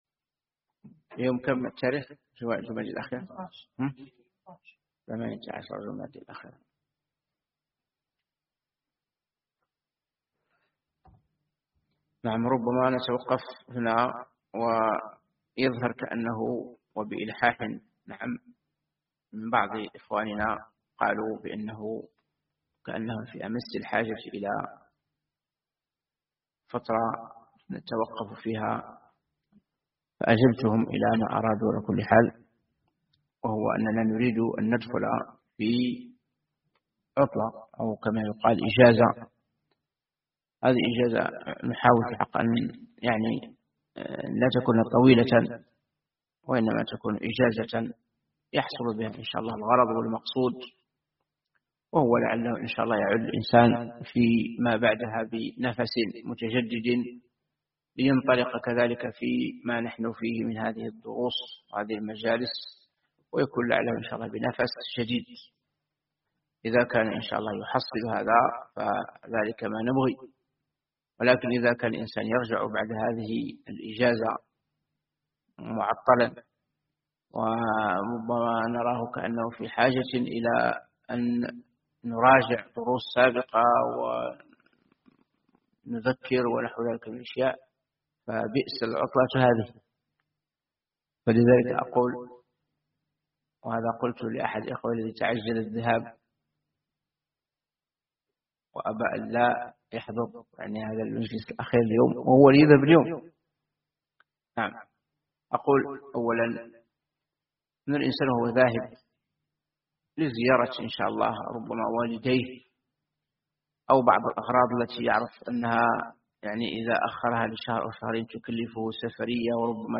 مواعظ ورقائق